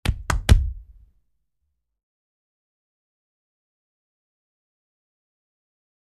Gorillas | Sneak On The Lot